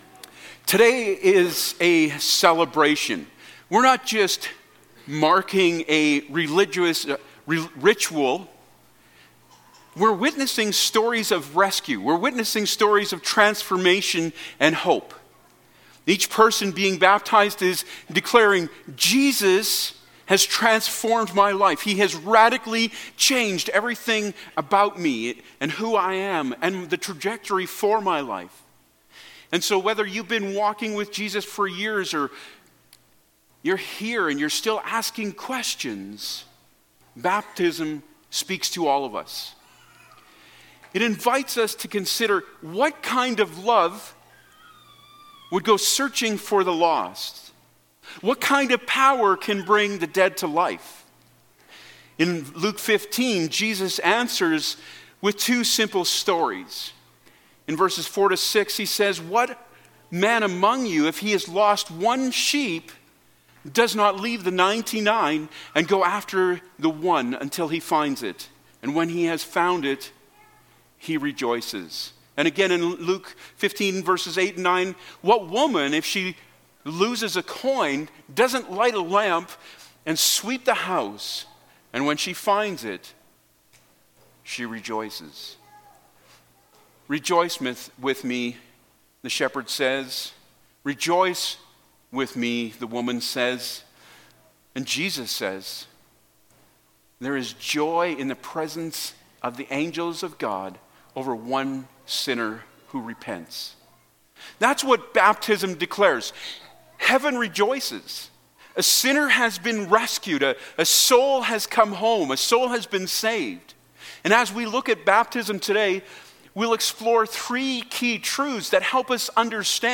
8-9 Service Type: Baptism Sunday Topics: Baptism